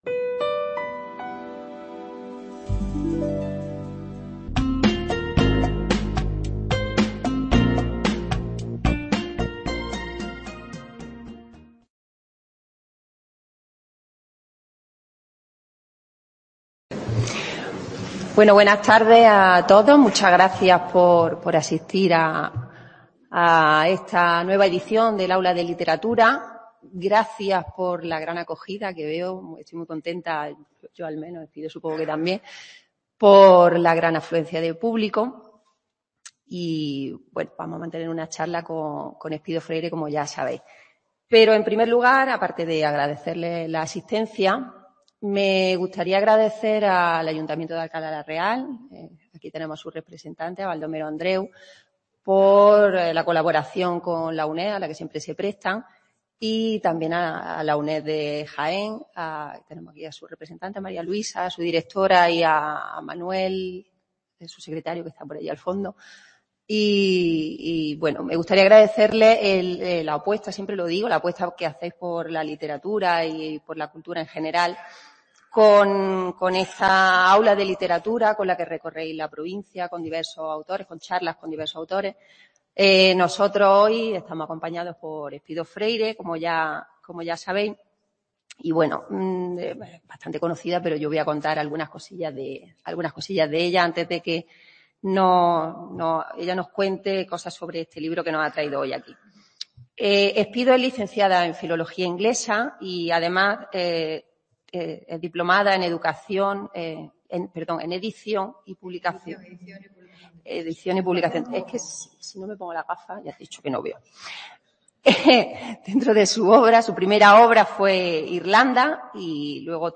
Encuentro literario con la escritora Espido Freire
en el Convento de Capuchinos de Alcalá la Real.